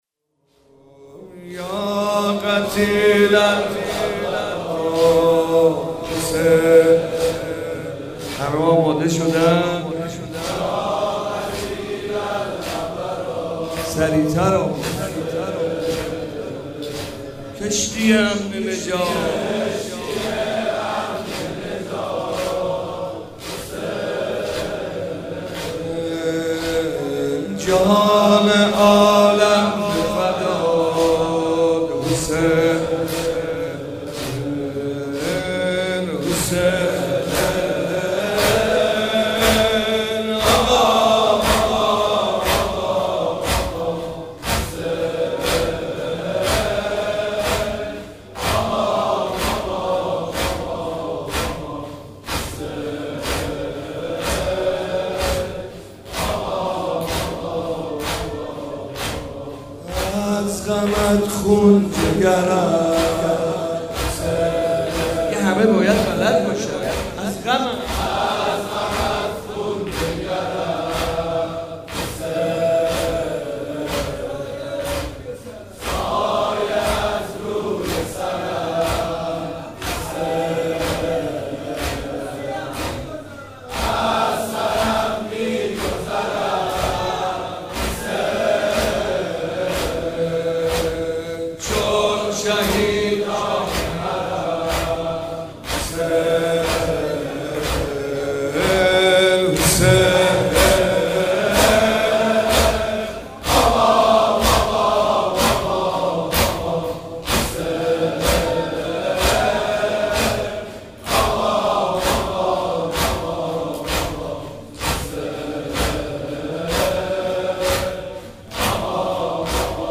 شب هشتم محرم95/هیئت مکتب الزهرا(س)